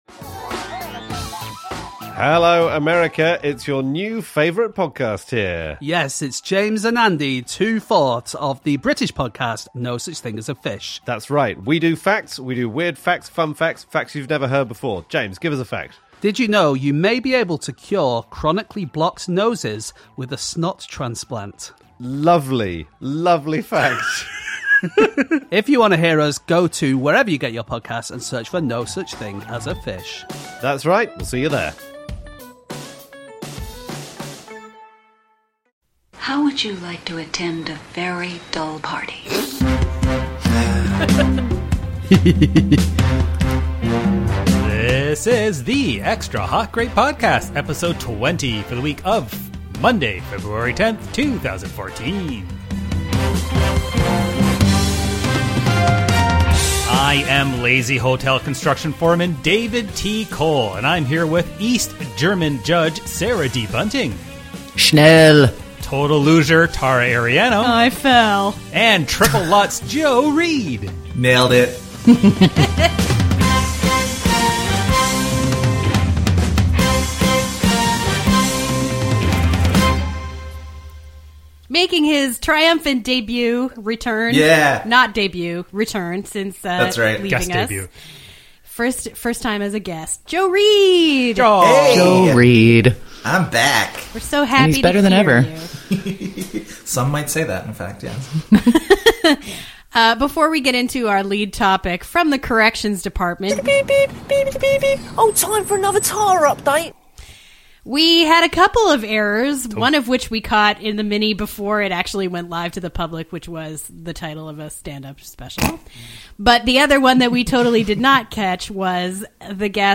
Listen with your most patriotic spirit!Production Note: Sorry about the on-air gravel eating.